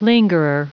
Prononciation du mot lingerer en anglais (fichier audio)
Prononciation du mot : lingerer